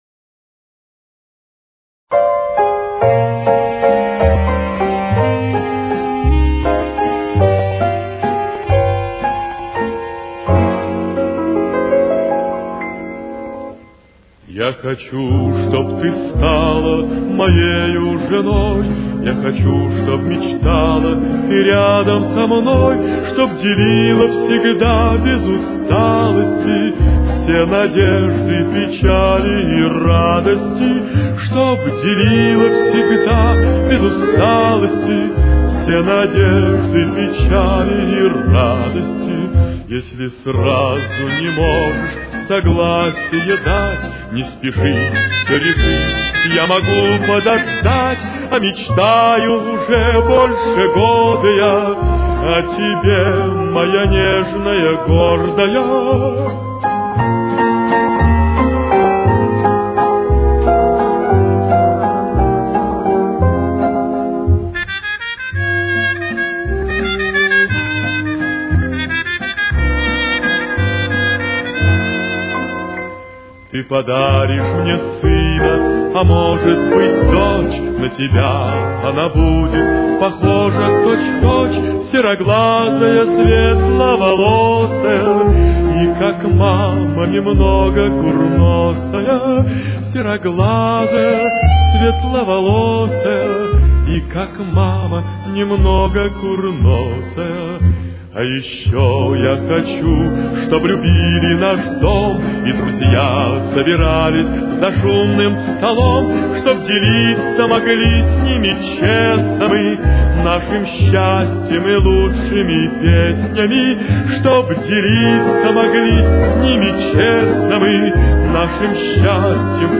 Темп: 176.